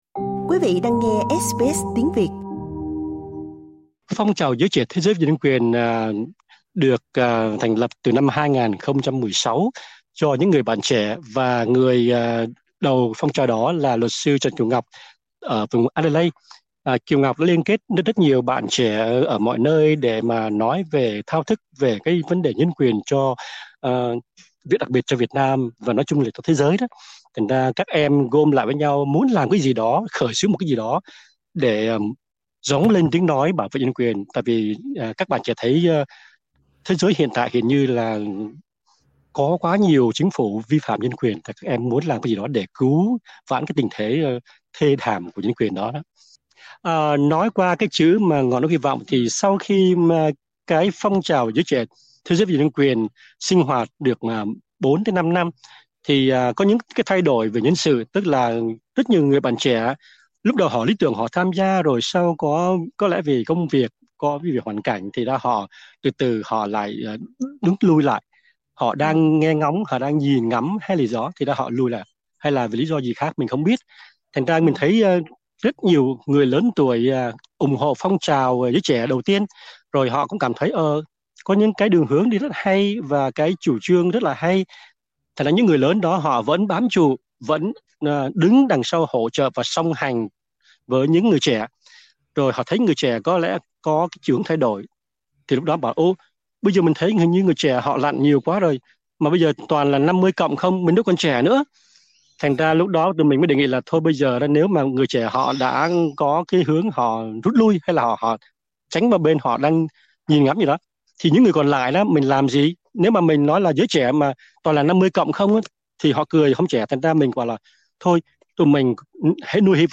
Xin mời quý vị nghe cuộc trò chuyện ở phần audio.